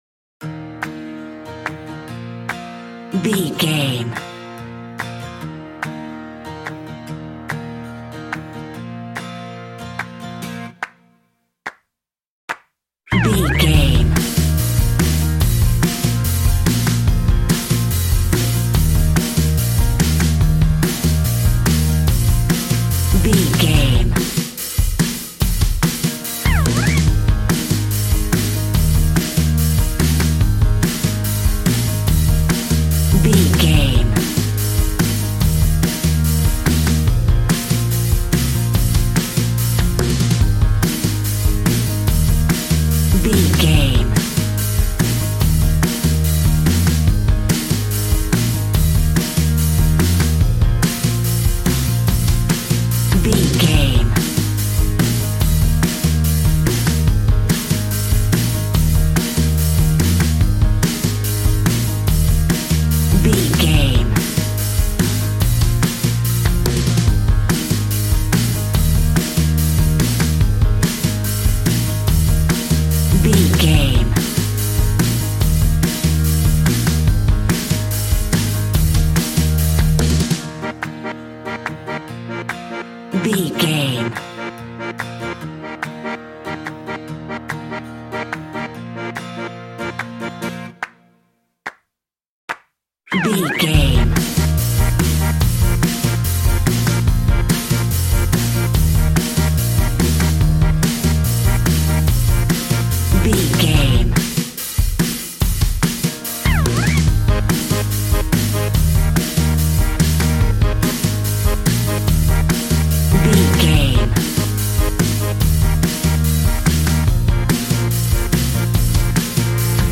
Ionian/Major
E♭
cheerful/happy
bouncy
electric piano
electric guitar
drum machine